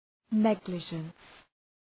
{‘neglıdʒəns}